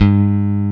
EPM E-BASS.2.wav